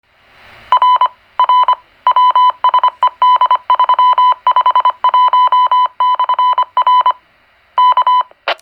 CW_GMRS_ID_RPTR